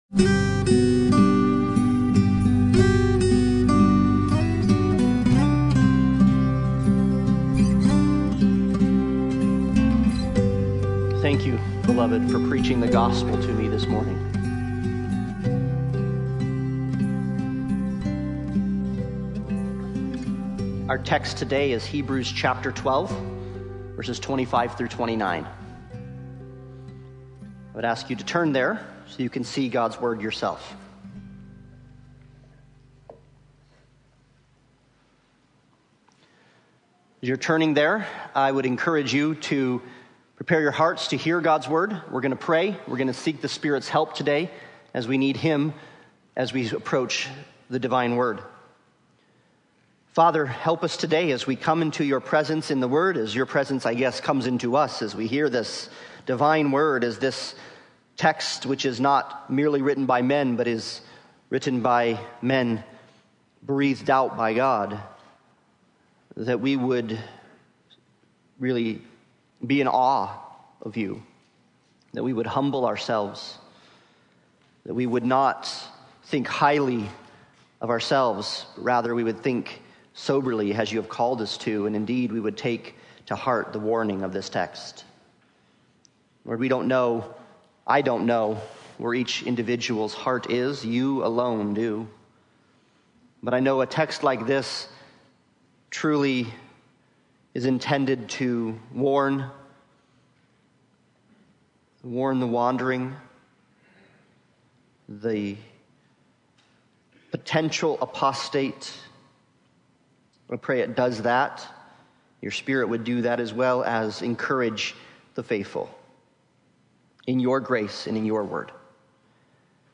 Hebrews 12:25-29 Service Type: Sunday Morning Worship « Adoption Sanctification »